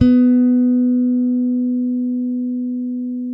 -MM JAZZ B 4.wav